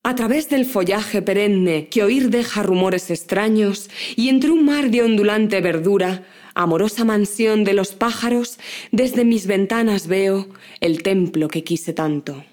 mujer
Sonidos: Voz humana
Sonidos: Textos literarios